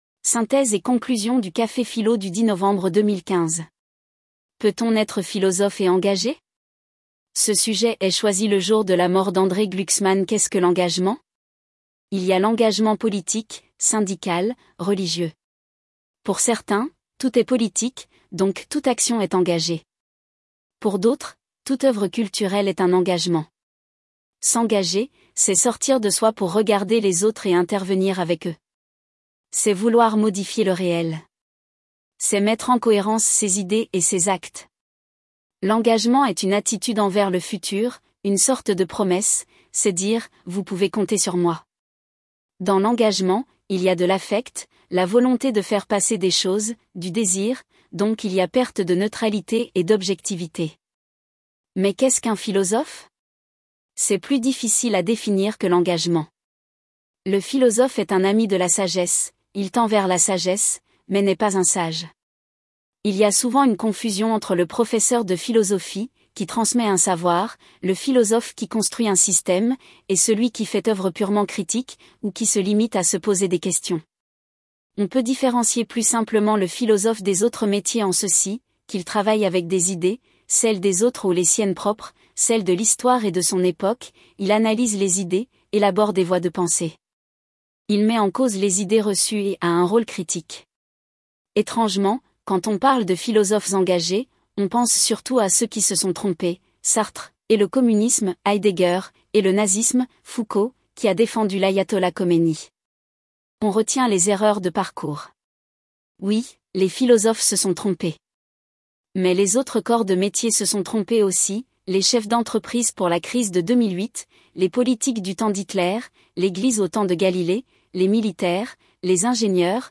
Synthèse et conclusion du café-philo du 10 Novembre 2015